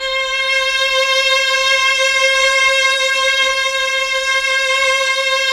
Index of /90_sSampleCDs/Keyboards of The 60's and 70's - CD1/KEY_Chamberlin/STR_Chambrln Str
STR_Chb StrC_6-L.wav